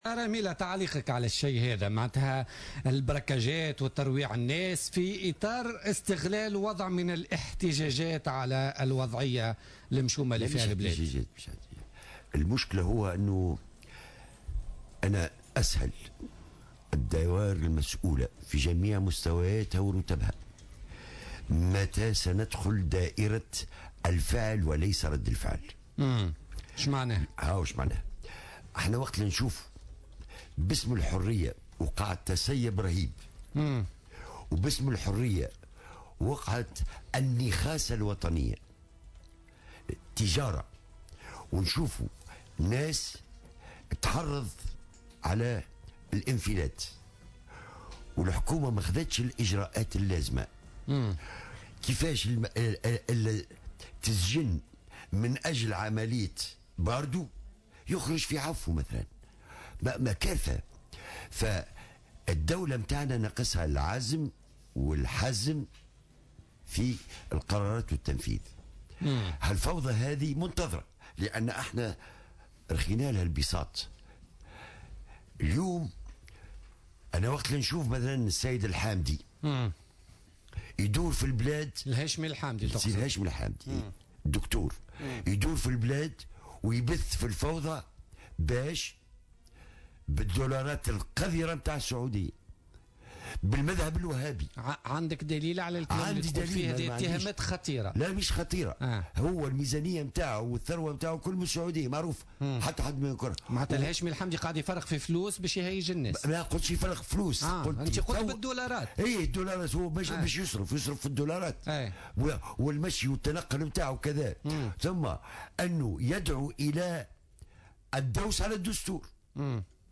اتهم الطاهر هميلة النائب السابق بمجلس الشعب ورئيس حزب الإقلاع ، ضيف برنامج بوليتكا لليوم الاثنين 25 جانفي 2016 رئيس تيار المحبة الهاشمي الحامدي ببث الفوضى في البلاد بدولارات السعودية "القذرة" وبالمذهب الوهابي" على حد تعبيره.